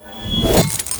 bsword2.wav